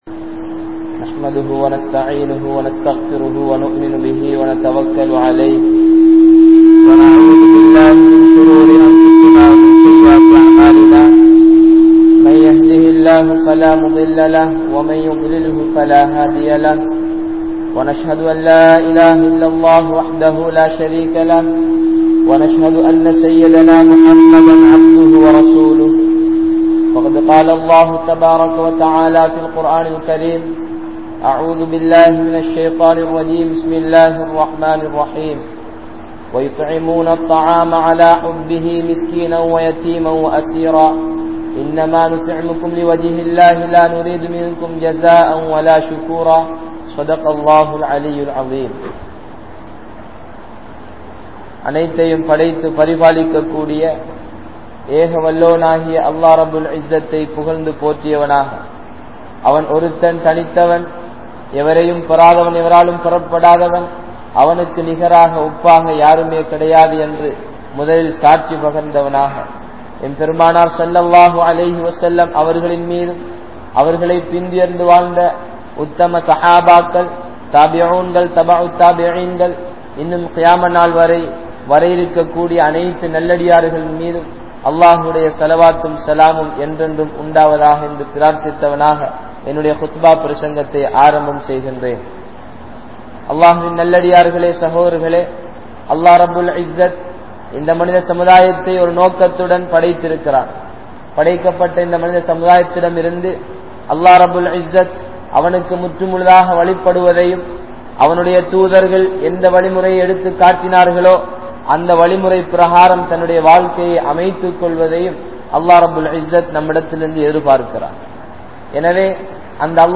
Sahabaakkalin Thiyaaham (ஸஹாபாக்களின் தியாகம்) | Audio Bayans | All Ceylon Muslim Youth Community | Addalaichenai
South Eastern University Jumua Masjith